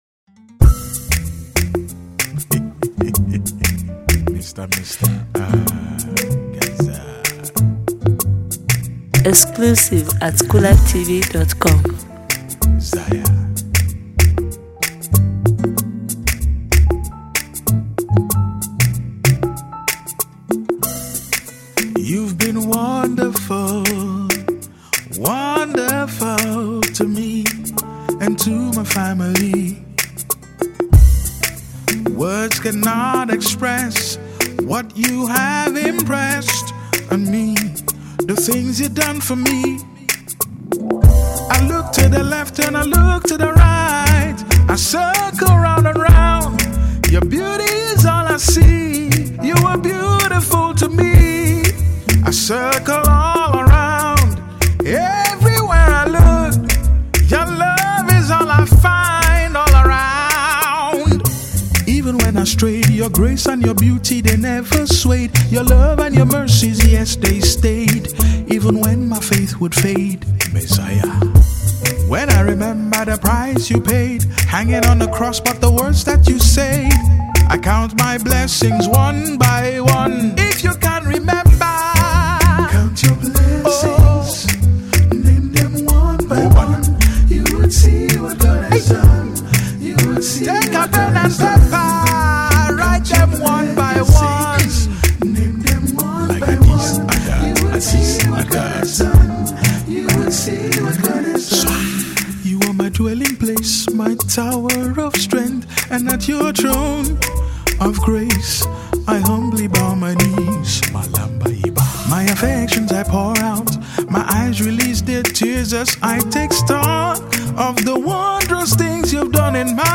/ Published in GAMBIA VIDEO, GOSPEL